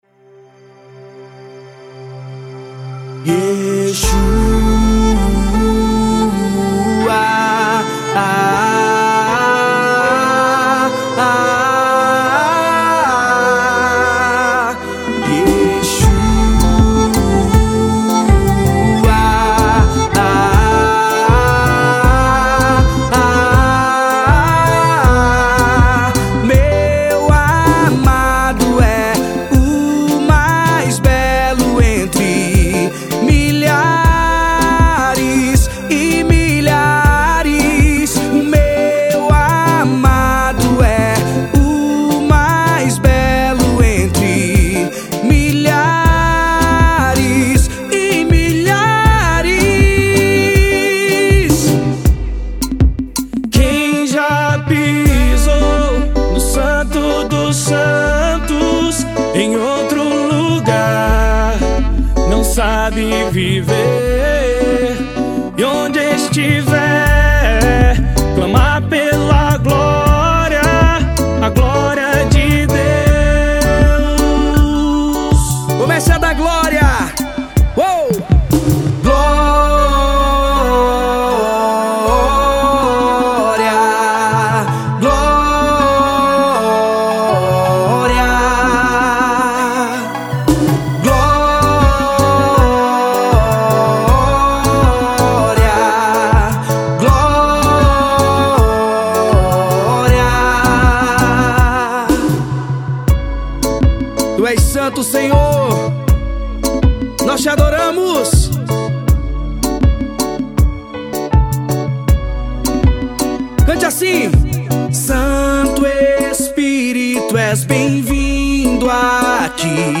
Medley.